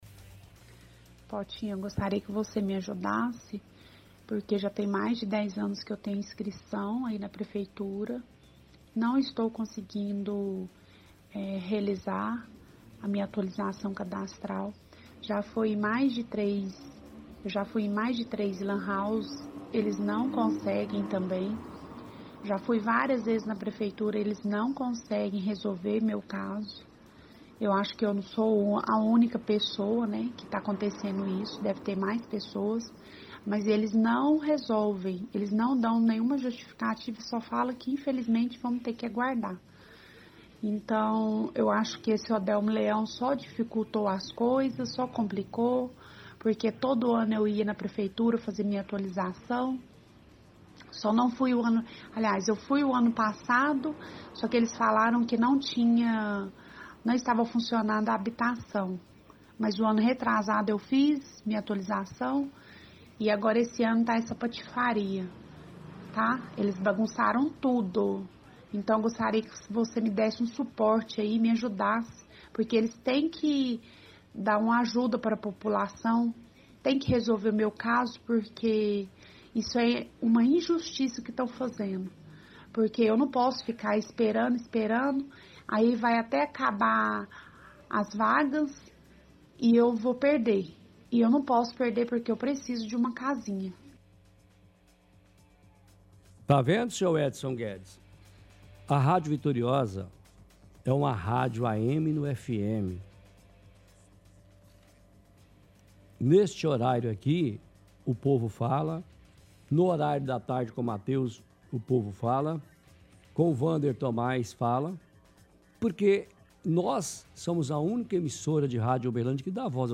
toca áudio de ontem em que ouvinte fala que está tendo problemas com a atualização do cadastro para o programa habitacional do governo federal.